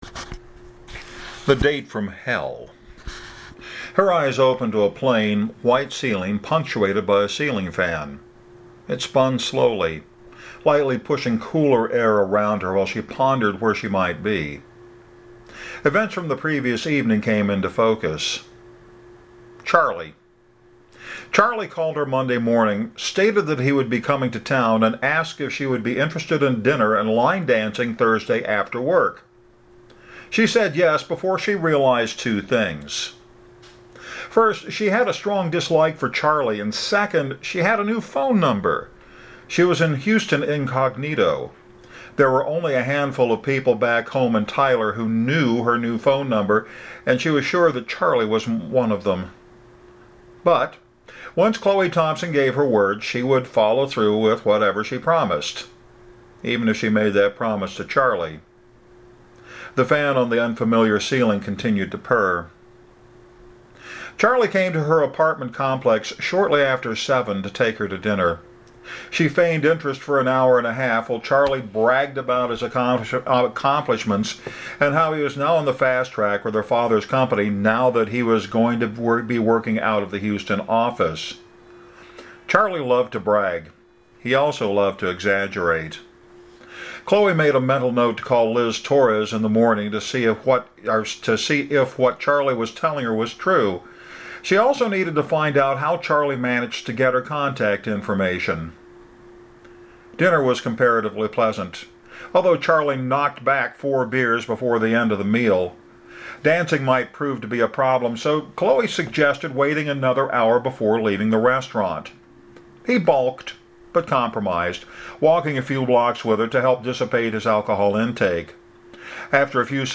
By the way, I sight-read the chapters instead of rehearsing them (in most instances).